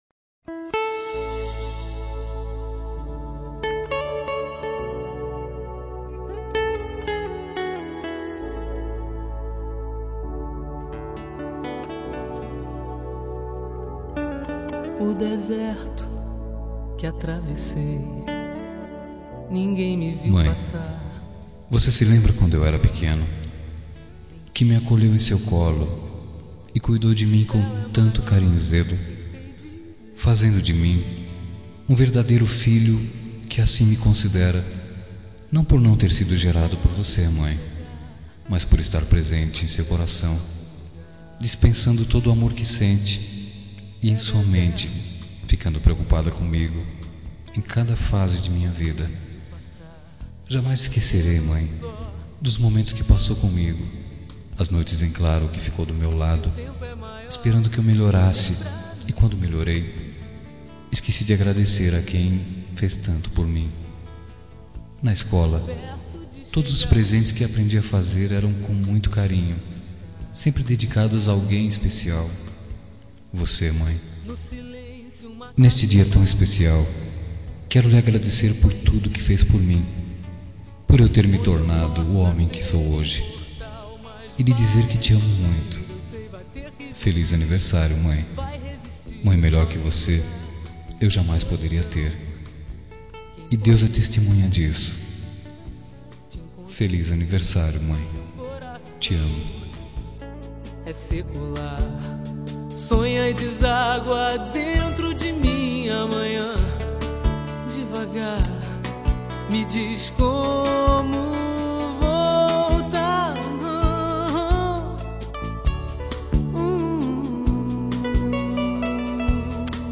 Telemensagem de Aniversário de Mãe – Voz Masculina – Cód: 1442 – Madrasta